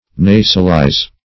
Nasalize \Na"sal*ize\, v. t.